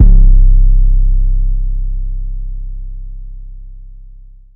WV [808]  coordinate u.wav